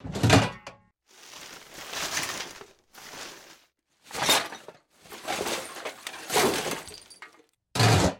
garbage_can_1.ogg